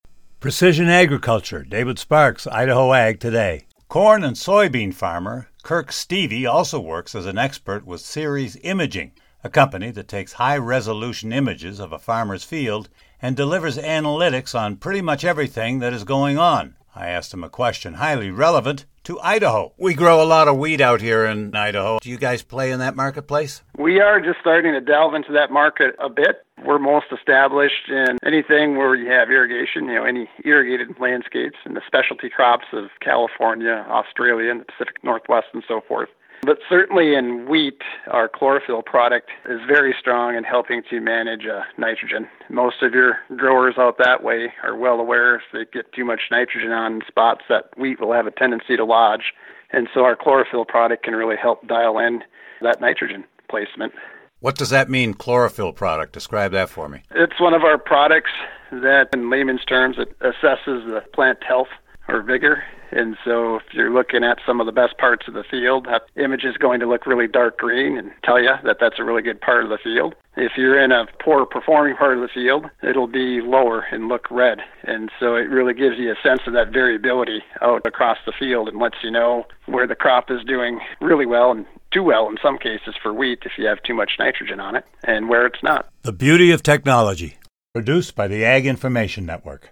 Precision agriculture.